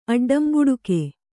♪ aḍḍambuḍuke